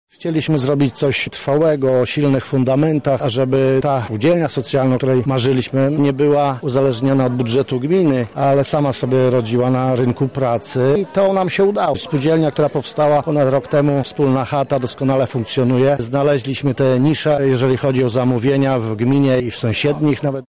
Laureatem w kategorii „Samorząd przyjazny ekonomii społecznej” została Gmina Tuczna. Wójt Gminy, Zygmunt Litwiński przekazał Radiu Centrum, że na ten cel udało się przeznaczyć pół miliona złotych.